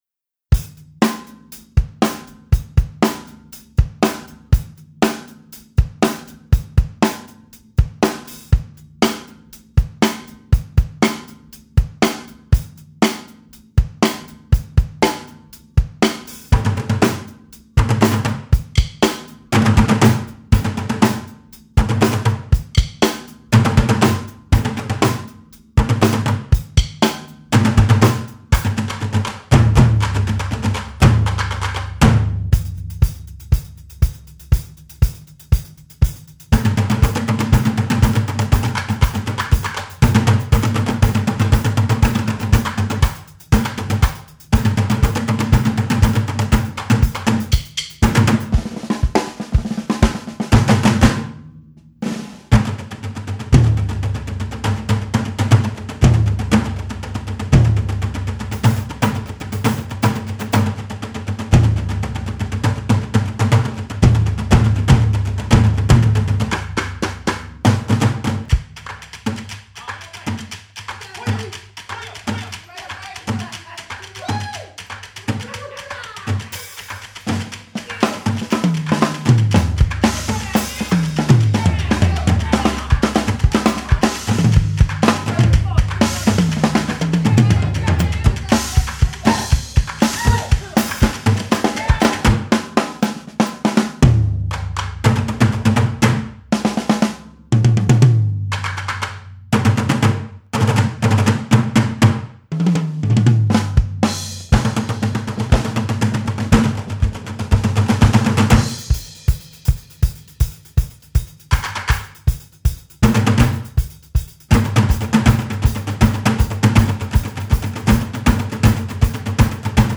Voicing: Percussion Nonet